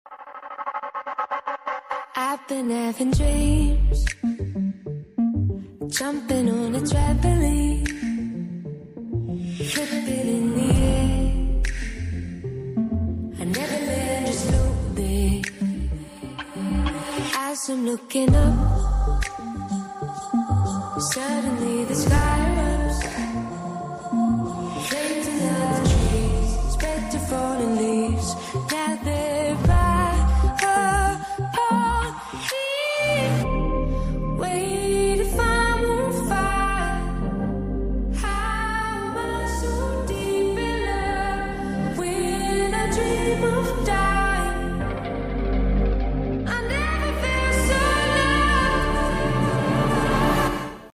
🎧🤔 If vou have trouble focusing with ADHD, try listening to bilateral stimulation music. Binaural beats are a type of auditory beat stimulation which have the potential for improved concentration and increased calm, something that can help with focus for people with ADHD. They happen when you listen to a sound at a certain frequency with one ear and a sound at a different but similar frequency with the other ear.